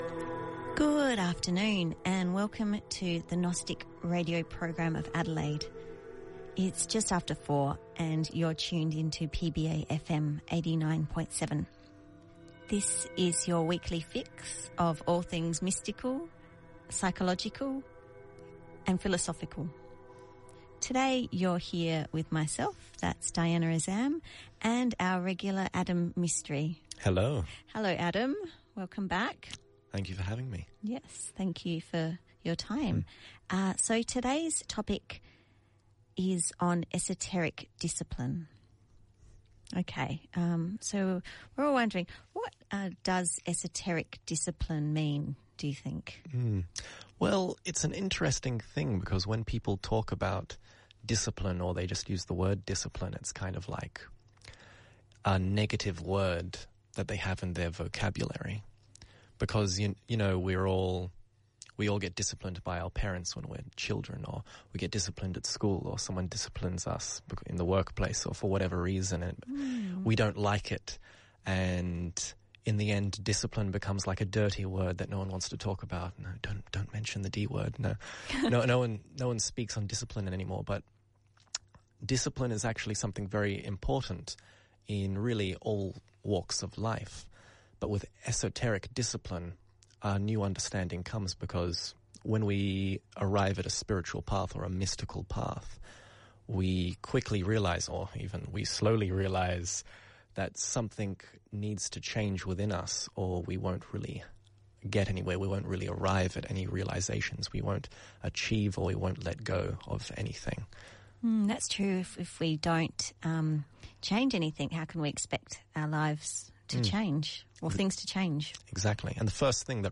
*Note: Due to technical difficulties, small parts of this recording are missing*